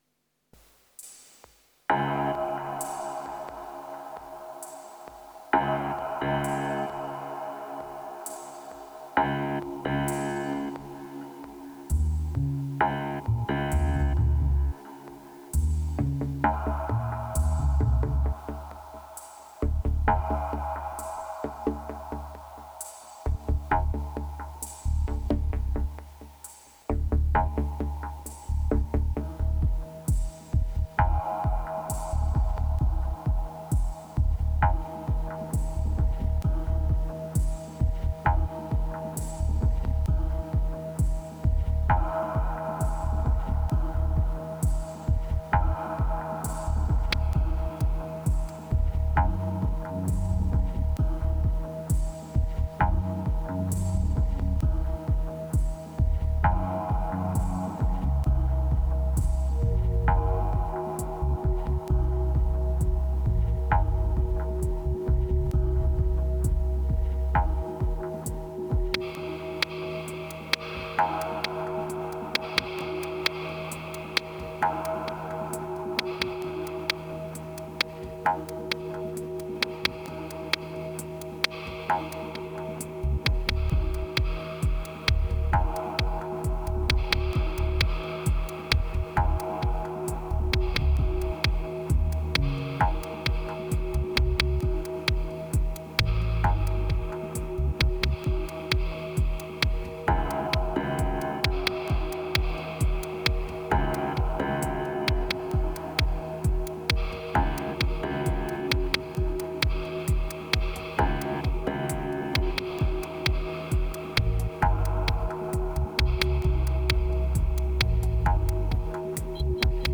2398📈 - 1%🤔 - 66BPM🔊 - 2017-06-07📅 - -240🌟